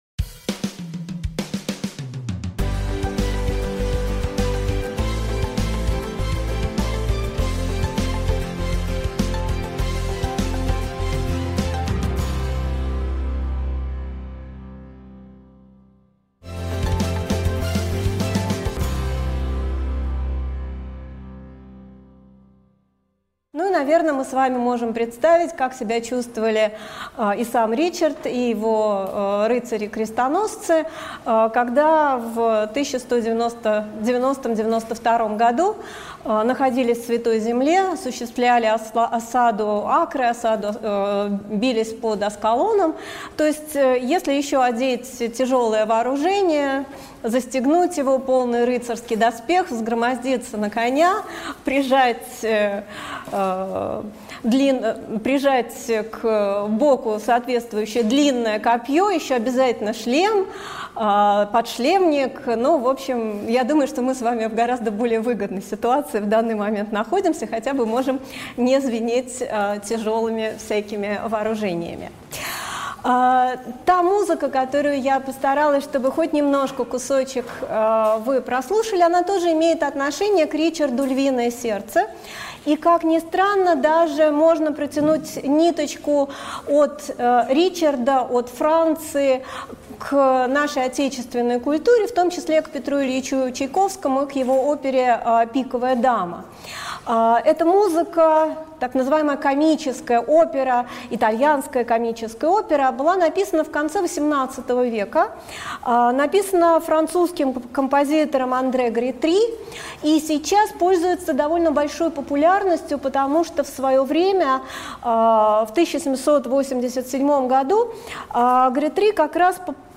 Аудиокнига Легендарные английские короли: Ричард Львиное Сердце | Библиотека аудиокниг